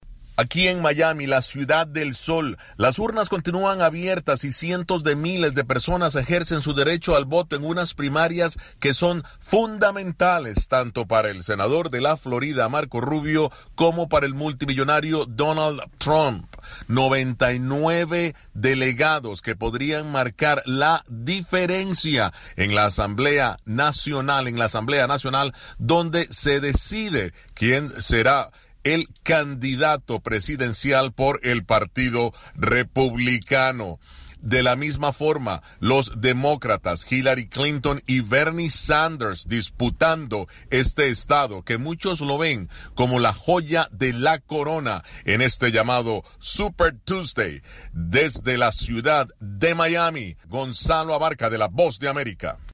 Las elecciones primarias en el crucial estado de Florida continúan con el candidato Donald Trump como favorito por los republicanos y Hillary Clinton por los demócratas. Informa desde Miami